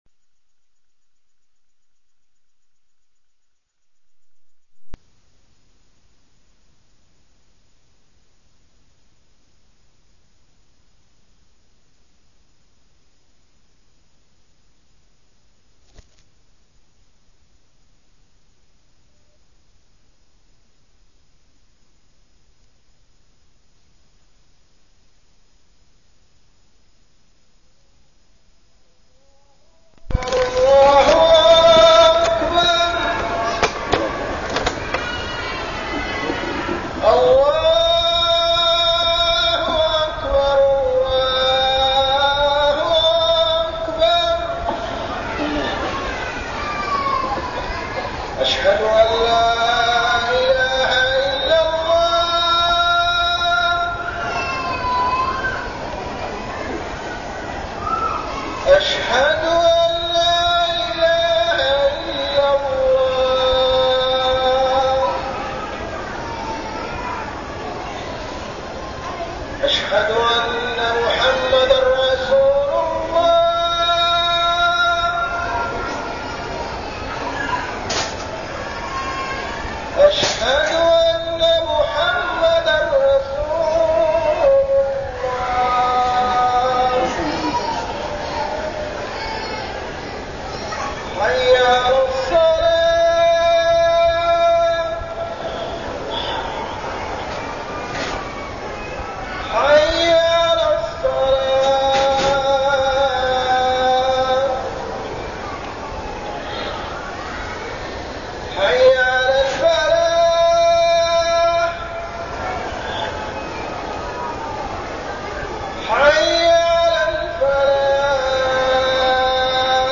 تاريخ النشر ٧ رجب ١٤١٠ هـ المكان: المسجد الحرام الشيخ: محمد بن عبد الله السبيل محمد بن عبد الله السبيل الذكر والذاكرون